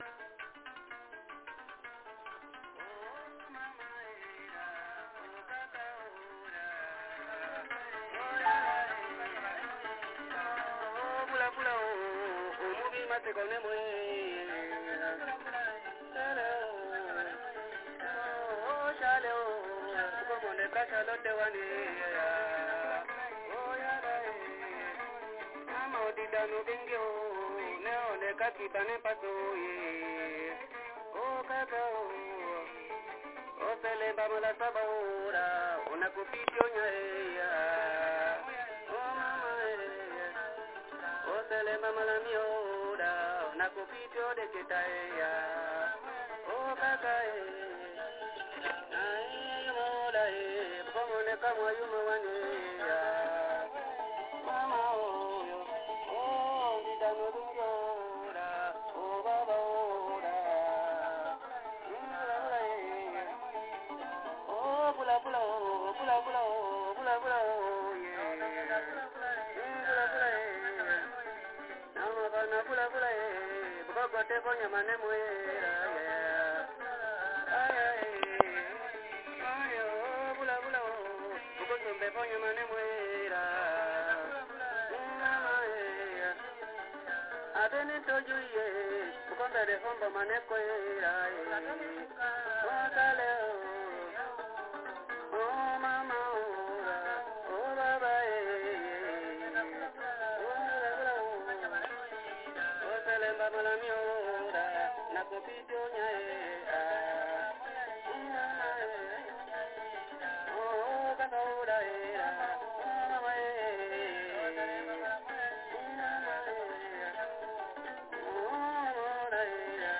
Griot | Asii ndea ɓakomo
Voici la chanson traditionnelle agrementée par le griot.